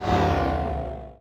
plasmadrop.ogg